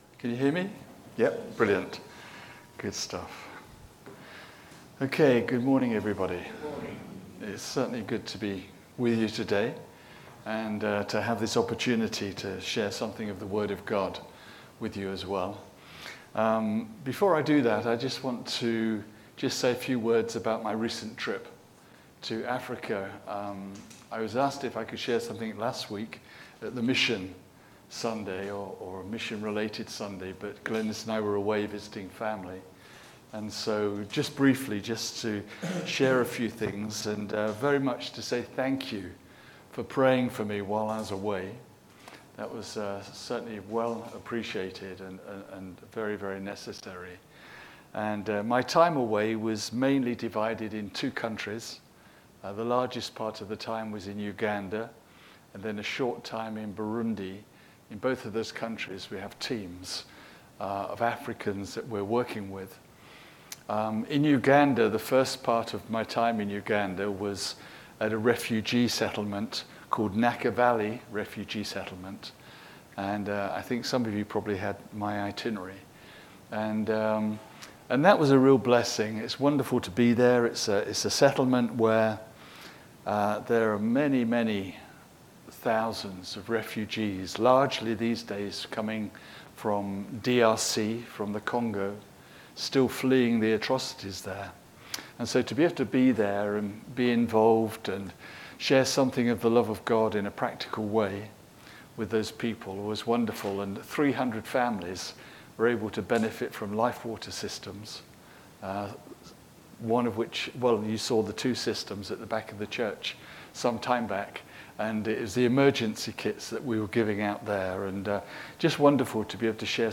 Media for a.m. Service on Sun 20th Jul 2025 10:30
Passage: Daniel 5, Series: Daniel - Faith in a foreign culture Theme: Sermon